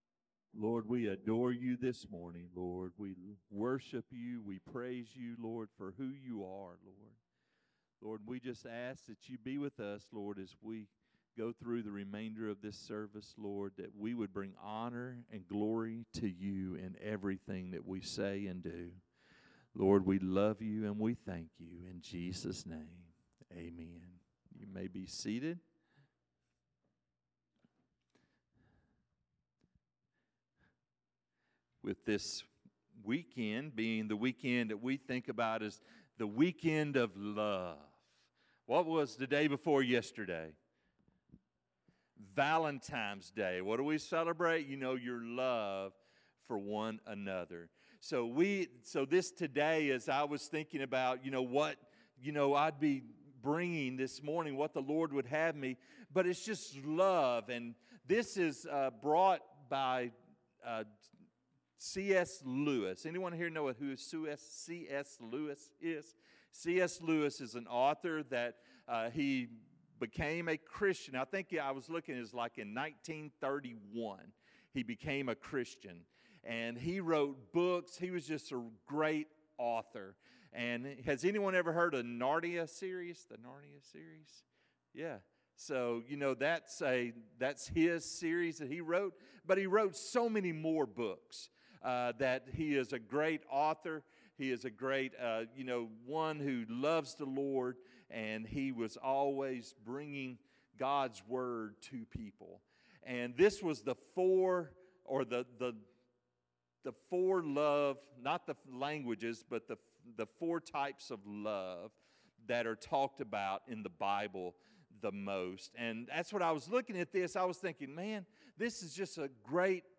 Sermons | First Southern Baptist Church Bearden